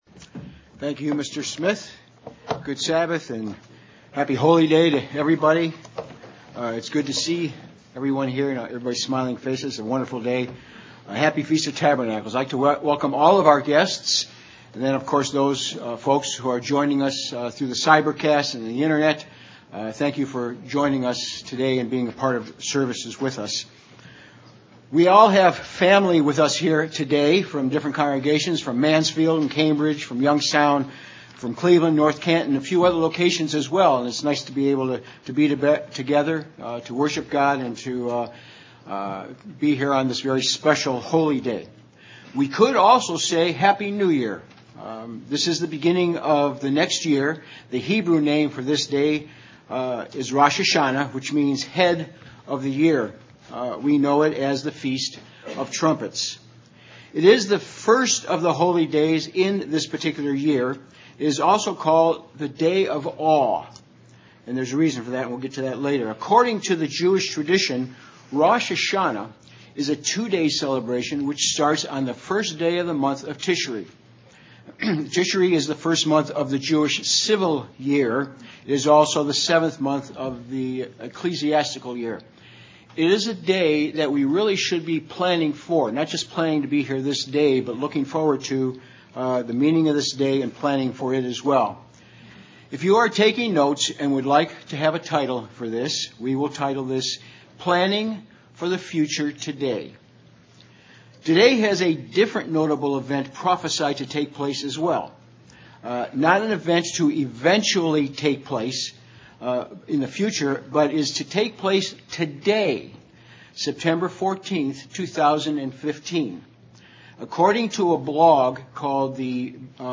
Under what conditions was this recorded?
Given in Cleveland, OH North Canton, OH